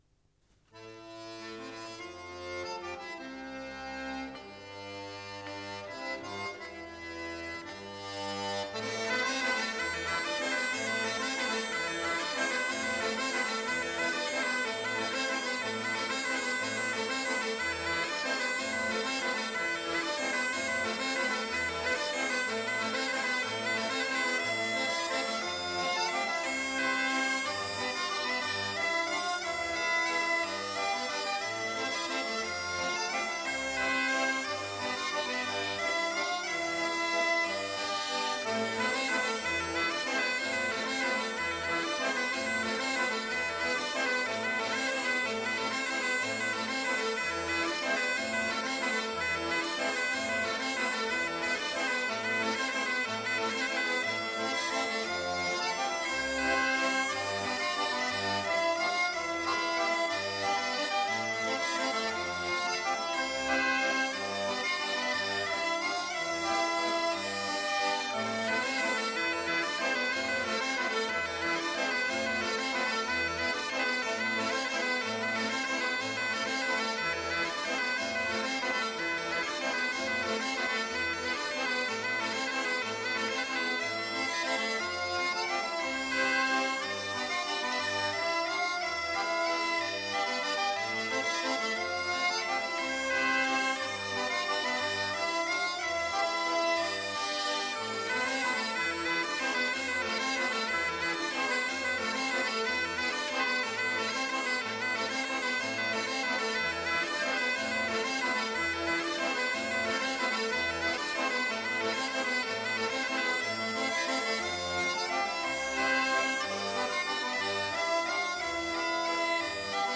enregistré à Seurre en décembre 1991